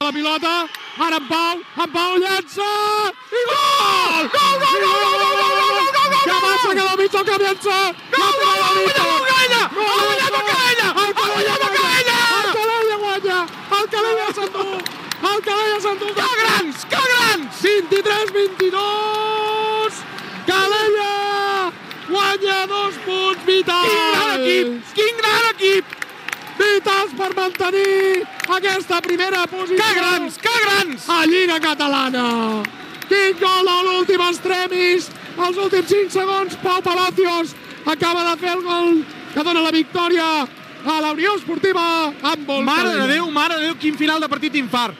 Narració dels últims segons del partit d'handbol masculí de la Lliga Catalana entre la Unió Esportiva Handbol Calella i el Gavà des del pavelló municipal d’esports Parc Dalmau
Esportiu